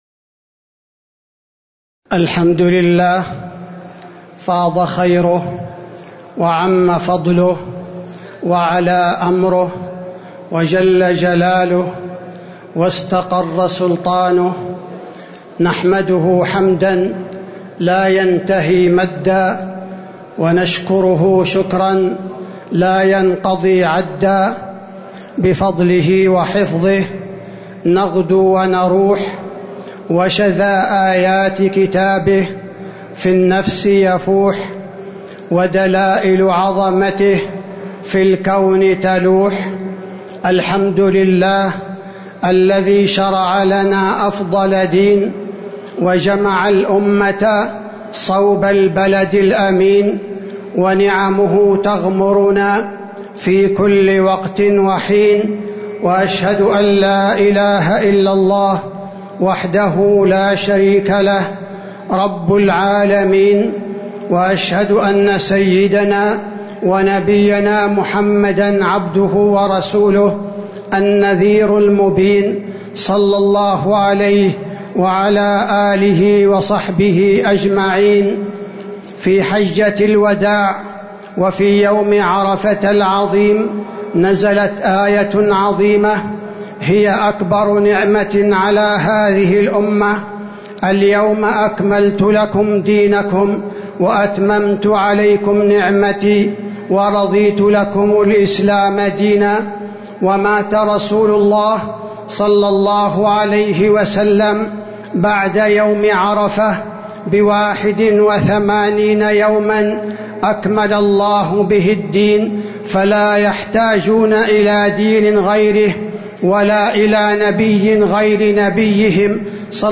خطبة عيد الأضحى - المدينة - الشيخ عبدالباري الثبيتي
تاريخ النشر ١٠ ذو الحجة ١٤٤٣ هـ المكان: المسجد النبوي الشيخ: فضيلة الشيخ عبدالباري الثبيتي فضيلة الشيخ عبدالباري الثبيتي خطبة عيد الأضحى - المدينة - الشيخ عبدالباري الثبيتي